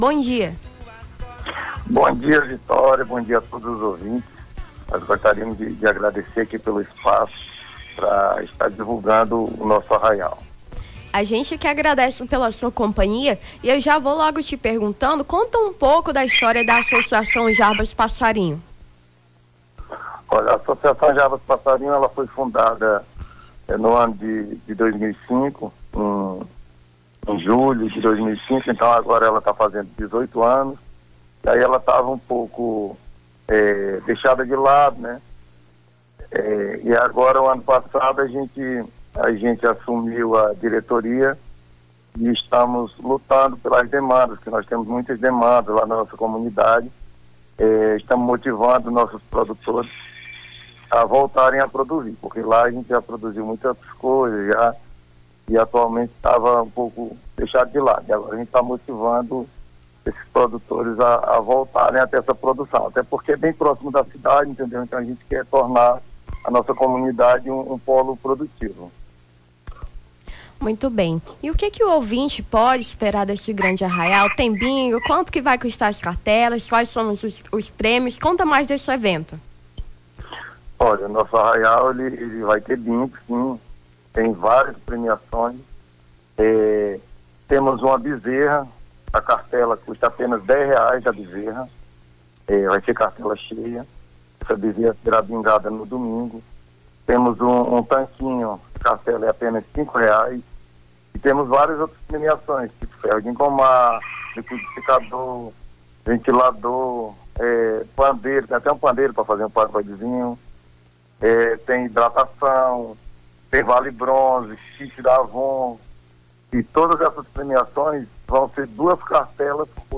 Nome do Artista - CENSURA - ENTREVISTA (ARRAIAL APOLONIO SALES) 28-07-23.mp3